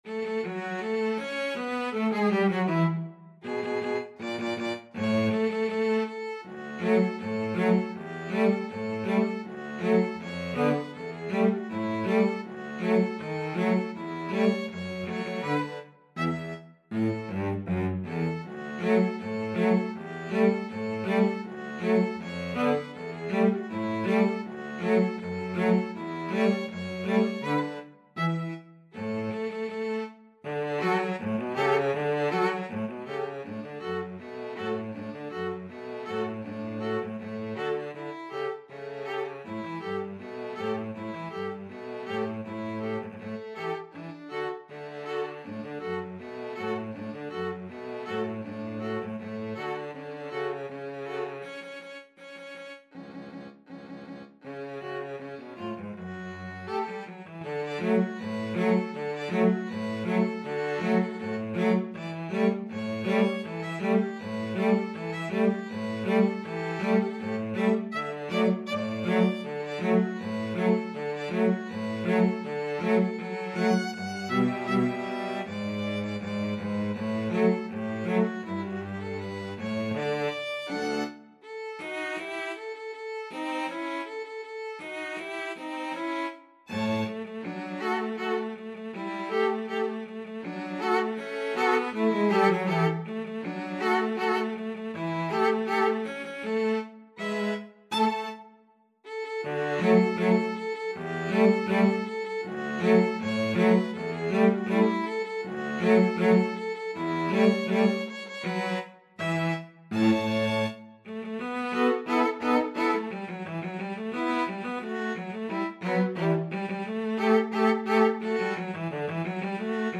"The Parade of the Tin Soldiers" (Die Parade der Zinnsoldaten), also known as "The Parade of the Wooden Soldiers", is an instrumental musical character piece, in the form of a popular jaunty march, written by German composer Leon Jessel, in 1897.
D major (Sounding Pitch) (View more D major Music for Violin-Cello Duet )
2/2 (View more 2/2 Music)
Not Fast =80
Violin-Cello Duet  (View more Intermediate Violin-Cello Duet Music)
Classical (View more Classical Violin-Cello Duet Music)